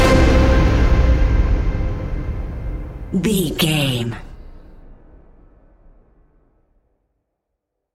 Epic / Action
Fast paced
In-crescendo
Ionian/Major
C♯
dark ambient
EBM
synths
Krautrock